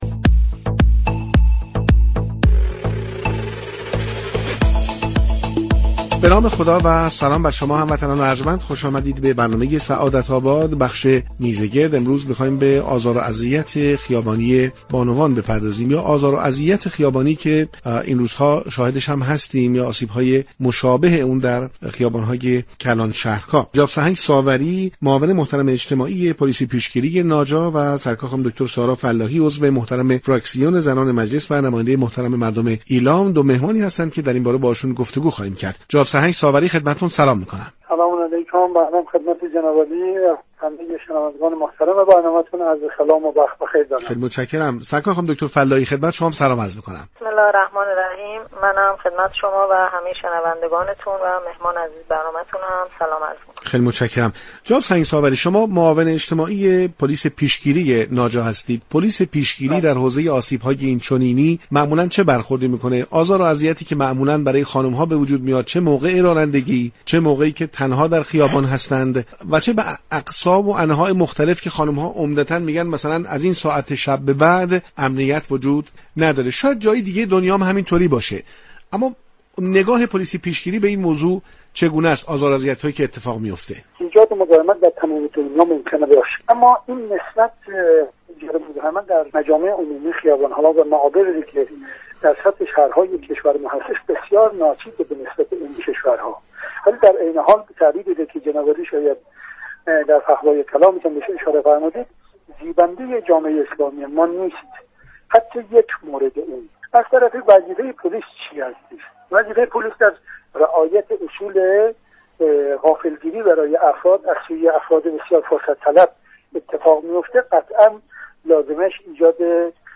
معاون اجتماعی پلیس پیشگیری ناجا، رئیس پلیس پیشگیری ناجا و سارا فلاحی نماینده مردم ایلام در مجلس شورای اسلامی با حضور در میزگرد برنامه سعادت آباد رادیو تهران در رابطه با آزار و اذیت های خیابانی علیه بانوان گفتگو كردند.